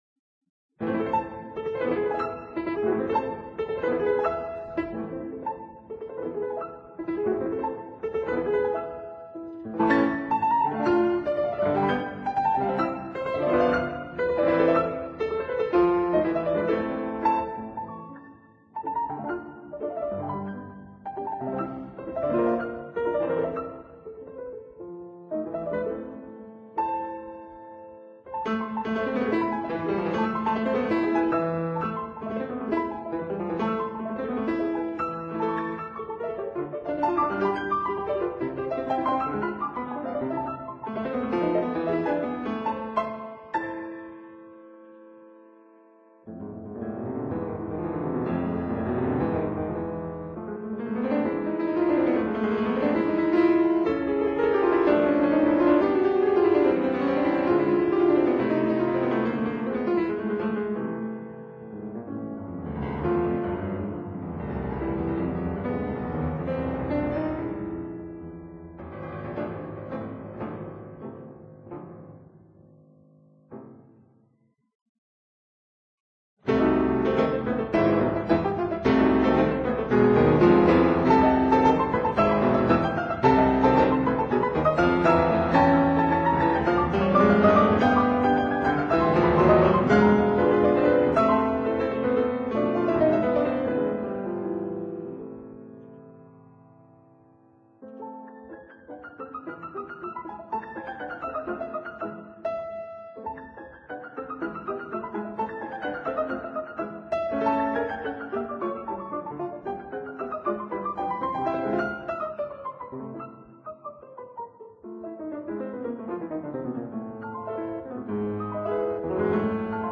제6곡 - a단조 쿠아시 프레스토 2/4박자 변주곡.
Alfred Brendel, Piano